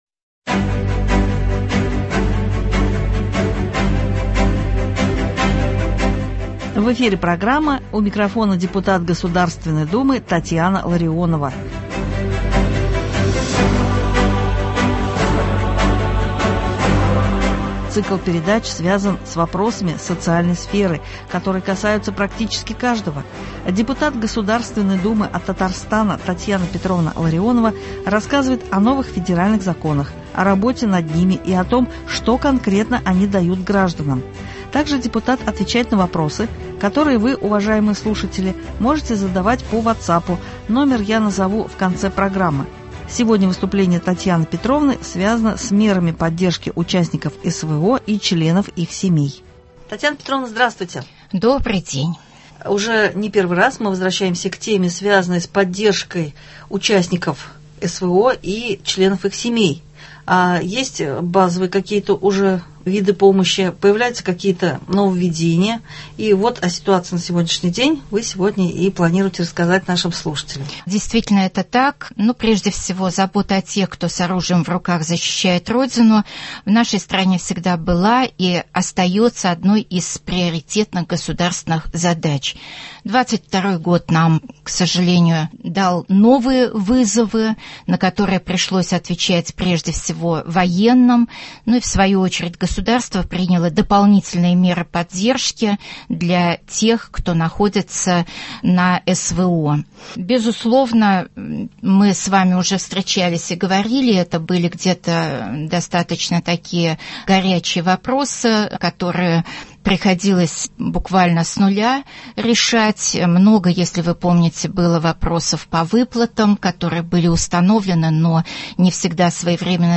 У микрофона депутат Государственной Думы Татьяна Ларионова. Сегодня речь — о мерах поддержки участников СВО и членов их семей.